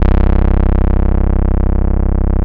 25SYN.BASS.wav